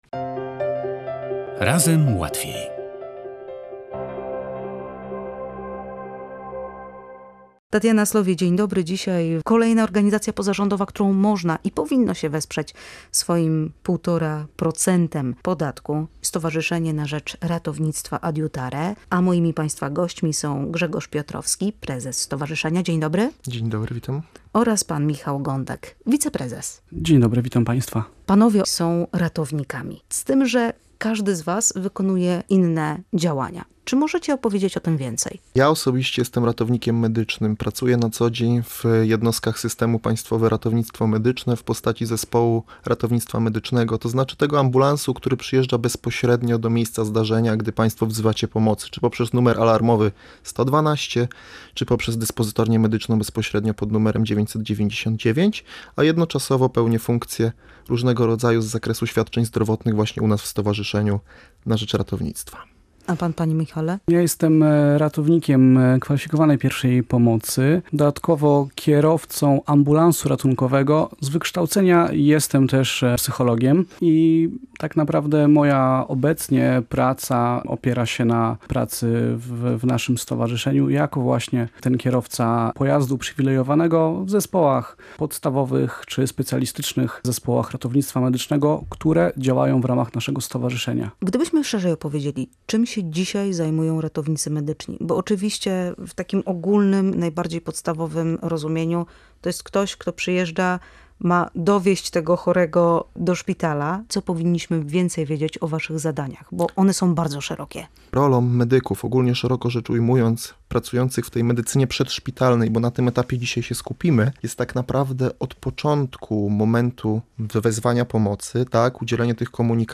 Nie bójmy się reagować i udzielajmy pierwszej pomocy – apelują ratownicy ze stowarzyszenia „Adiutare”